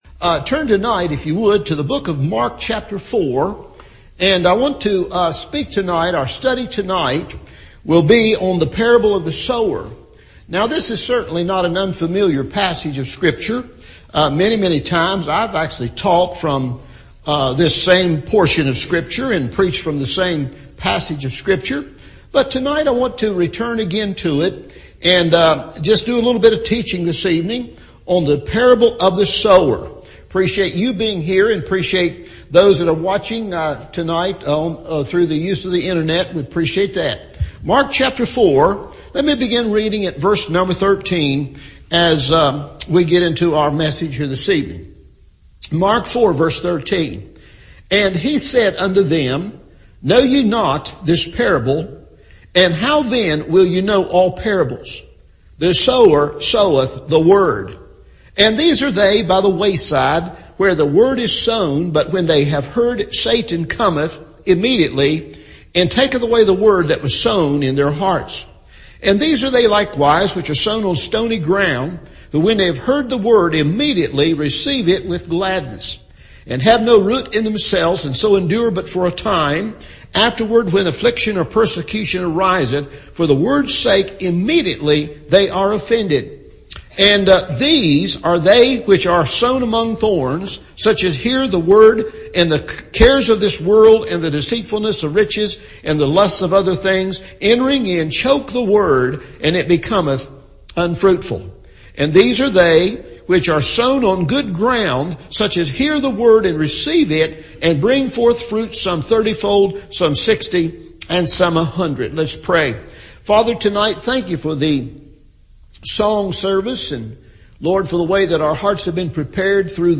Parable of the Sower – Evening Service